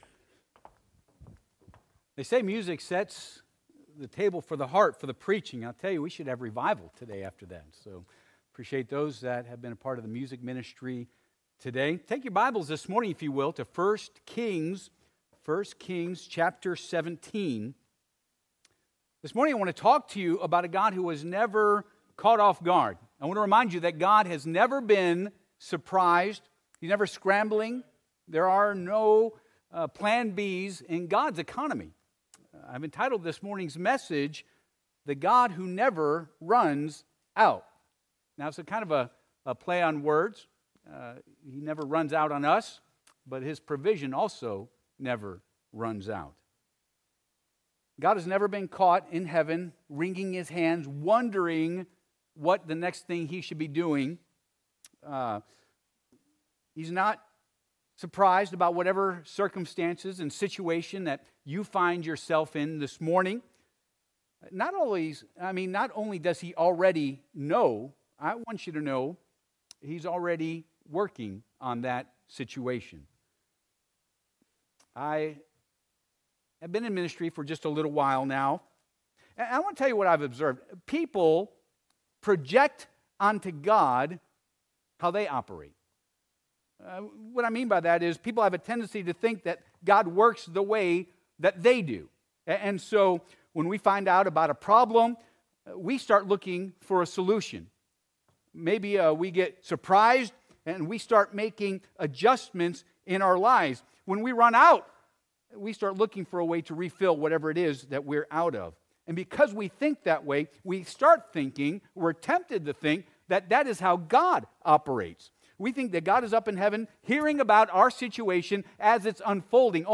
2026 Theme Passage: 1 Kings 17:1-24 Service Type: Sunday AM « Have You Been to Your Altar?